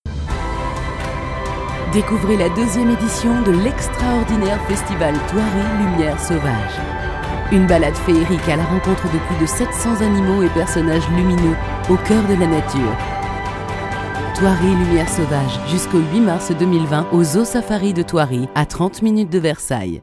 Female
Adult (30-50)
Deep voice, warm, sexy if required, used to sing rock jazz and blues, smoother on demand, and many others variations available.
Main Demo
All our voice actors have professional broadcast quality recording studios.